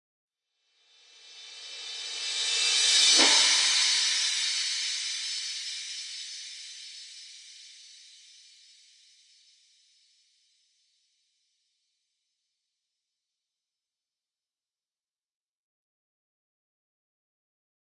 世界之战三脚架 " 世界之战号角2
标签： 电子 黄铜 成立以来 数字 长号 黑暗 大号 震动 吓人 吉布 世界
声道立体声